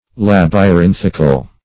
Search Result for " labyrinthical" : The Collaborative International Dictionary of English v.0.48: Labyrinthic \Lab`y*rin"thic\, Labyrinthical \Lab`y*rin`thic*al\, a. [L. labyrinthicus: cf. F. labyrinthique.] Like or pertaining to a labyrinth.
labyrinthical.mp3